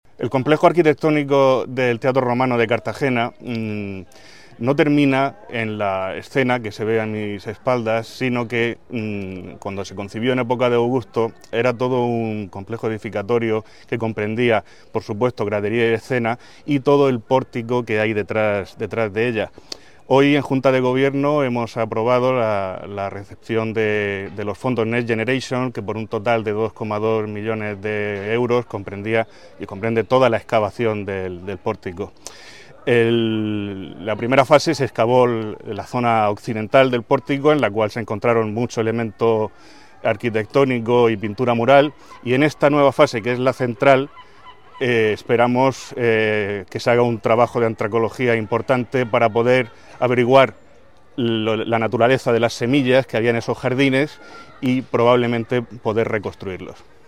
Enlace a Declaraciones del edil de Patrimonio Arqueológico, Pablo Braquehais.